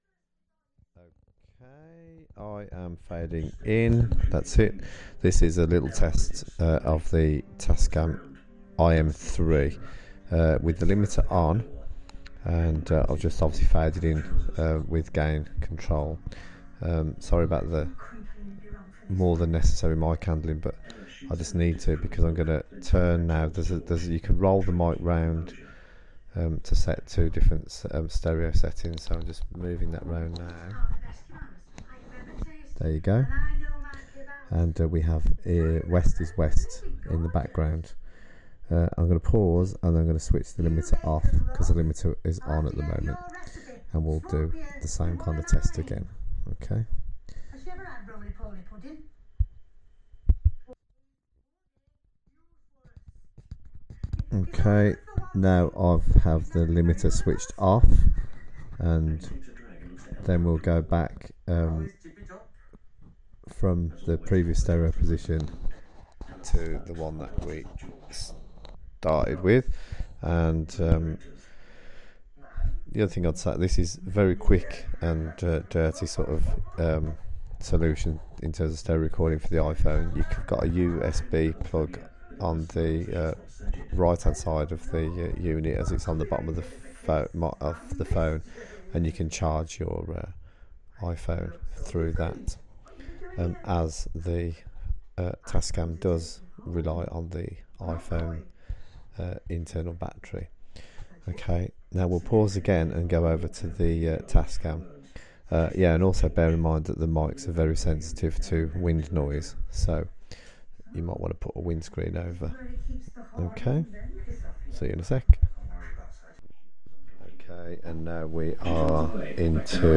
Quick comparison between IM2 and Fostex AR4I
As requested, here is the quick text comparison. Bit of IPhone mic at the end for good measure.